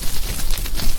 tree.ogg